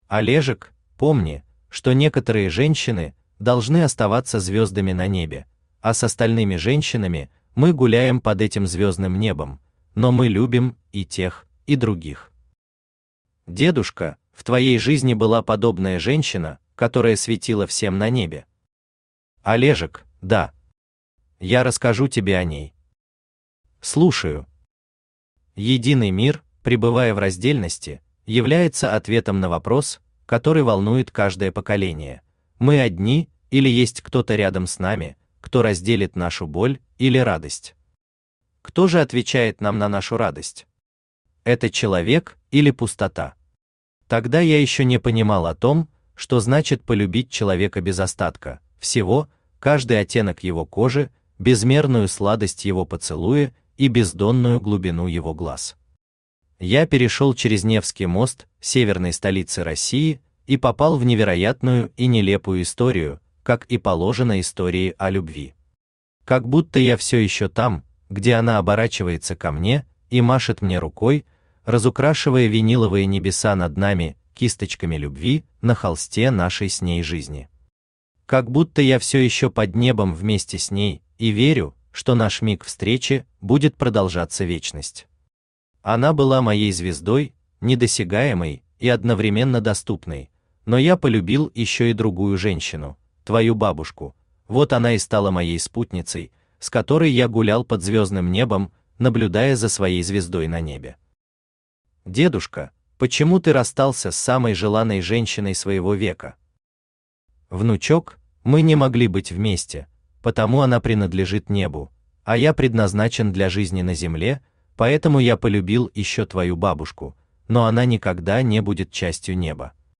Аудиокнига Под небом вместе с тобой | Библиотека аудиокниг
Aудиокнига Под небом вместе с тобой Автор Виталий Александрович Кириллов Читает аудиокнигу Авточтец ЛитРес.